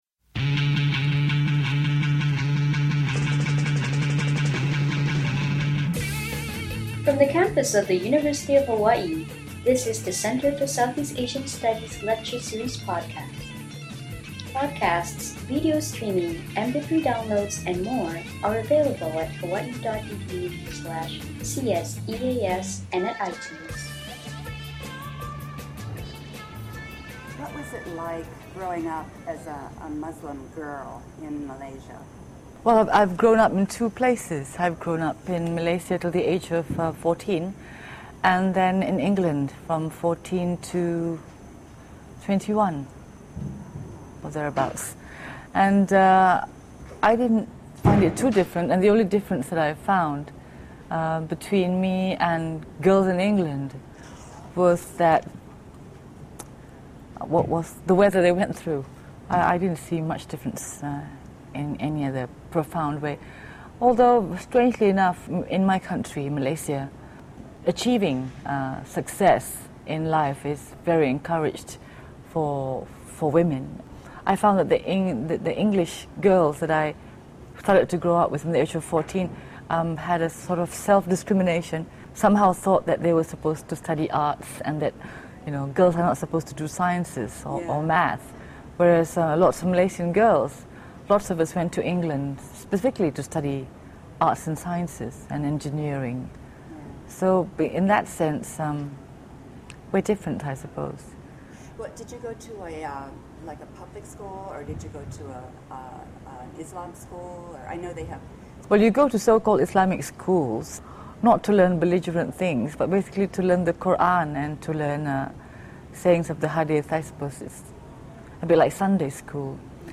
Interview with Malaysian Filmmaker Yasmin Ahmad
CSEAS hosted Malaysian auteur director Yasmin Ahmad at a retrospective of her films at the Honolulu Academy of Arts, including the US premiere of "Mukhsin". This podcast features Ahmad in conversation